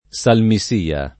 SalmiS&a] escl. — locuz. deprecativa (quasi «Dio ci salvi») dell’uso fam. tosc. tra il ’600 e l’800 — raro sal mi sia [id.] o sal’ mi sia [id.]: Tu ti morrai digiuno, sal mi sia [t2 tti morr#i diJ2no, Sal mi S&a] (M. Buonarroti il Giov.); tondi e panciuti da tutte le parti come, sal’ mi sia, Yorick [t1ndi e ppan©2ti da tt2tte le p#rti k1me, Sal mi S&a, L0rik] (Carducci) — ugualm. rara la forma senza tronc. salvo mi sia [S#lvo mi S&a]: Per esser liberal (salvo mi sia) Ci vuol testa [per $SSer liber#l (S#lvo mi S&a) ©i vUql t$Sta] (Giusti)